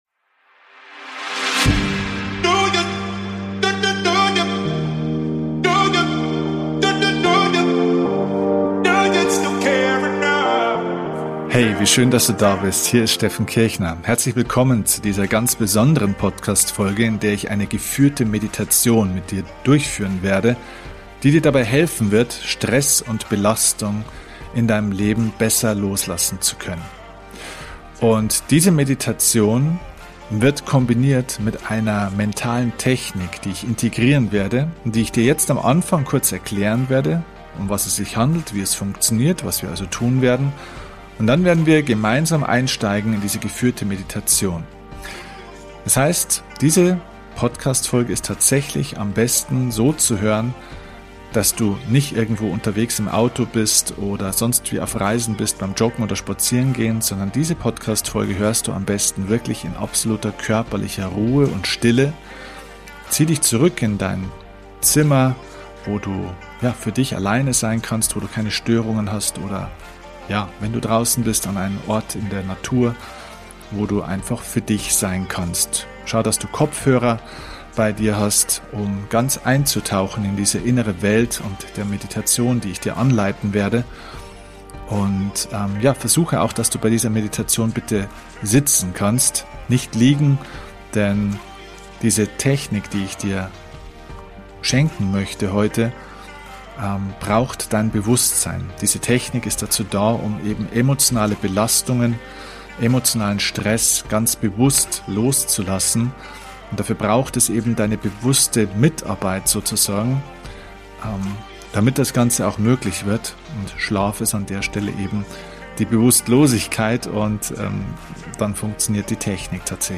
In dieser Folge des UPGRADE YOUR LIFE Podcast habe ich eine geführte Meditation für Dich. Gemeinsam werden wir Stress und Belastung loslassen und für mehr Entspannung in Deinem Leben sorgen!